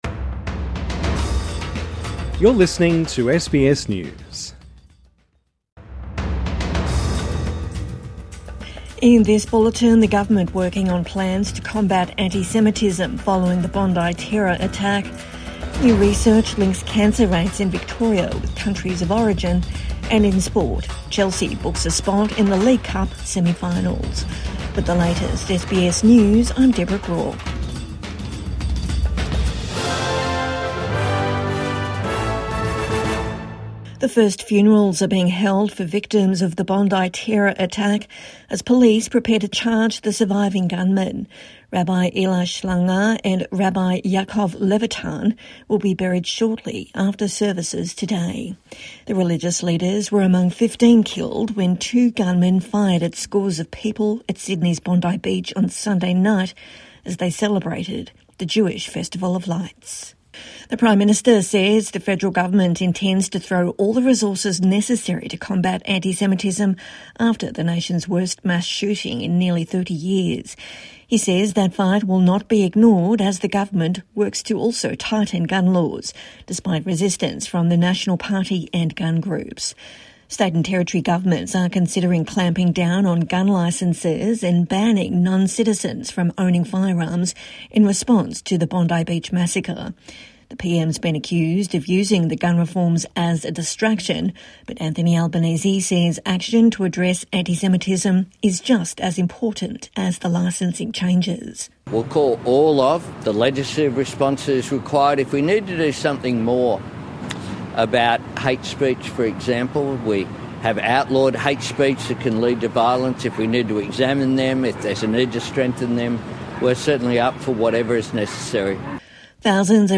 First funerals held for victims of Bondi mass shooting | Midday News Bulletin 17 December 2025